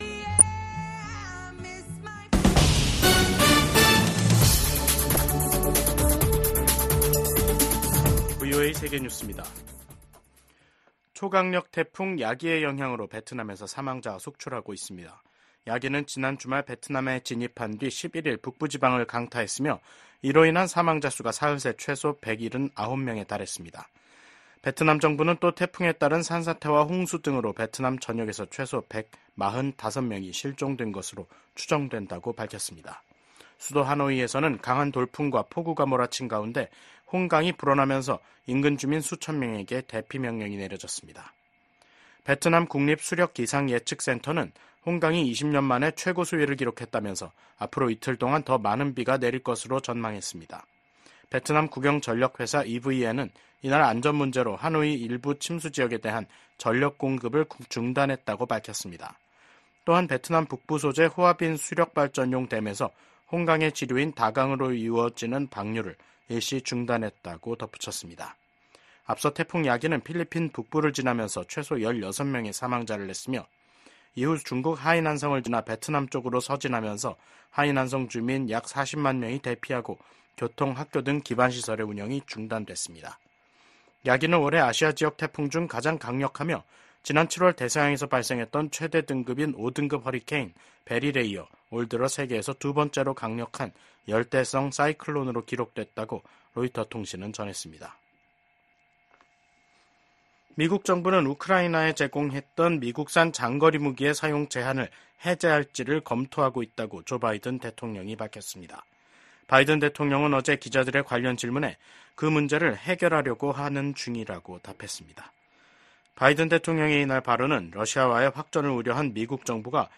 VOA 한국어 간판 뉴스 프로그램 '뉴스 투데이', 2024년 9월 11일 3부 방송입니다. 오는 11월 대선에서 맞붙는 카멀라 해리스 부통령과 도널드 트럼프 전 대통령이 TV 토론회에 참석해 치열한 공방을 벌였습니다. 미국, 한국, 일본 간 협력 강화를 독려하는 결의안이 미 하원 본회의를 통과했습니다. 서울에선 68개 국가와 국제기구 고위 인사들이 참석한 가운데 다자 안보회의체인 서울안보대화가 열렸습니다.